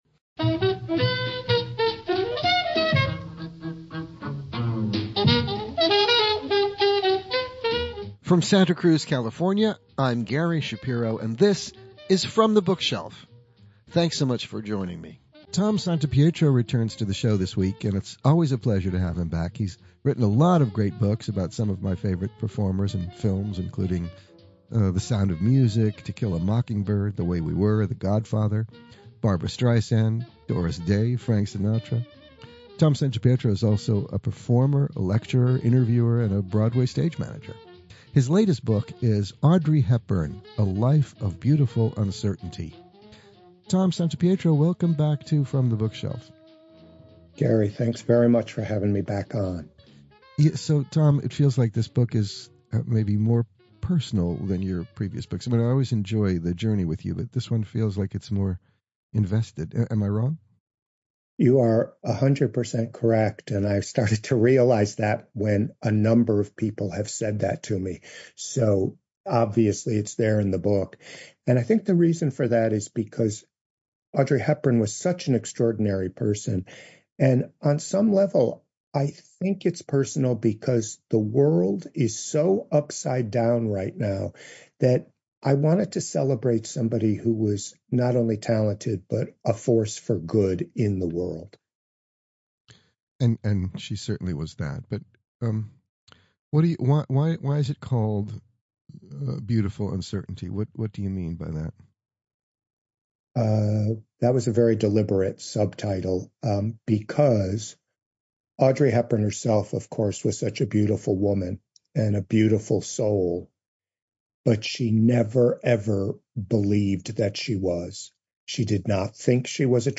From the Bookshelf is heard on radio station KSQD in Santa Cruz California.